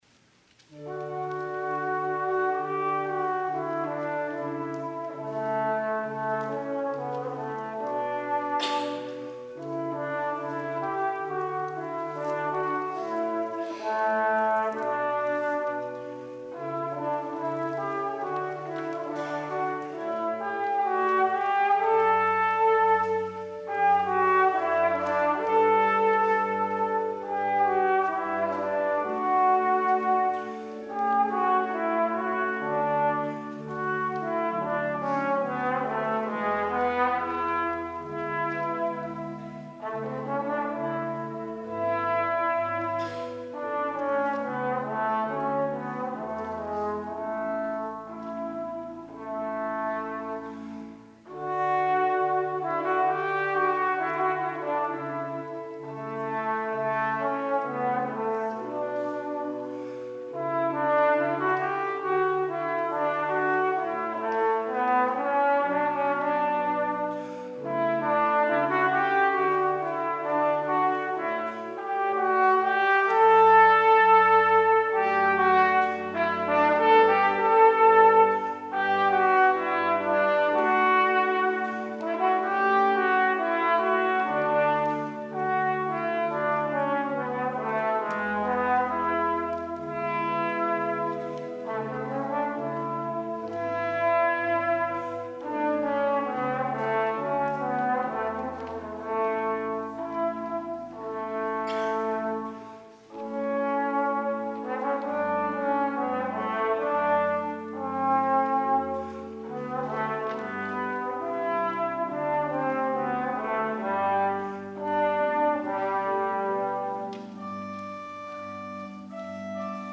trombone
organ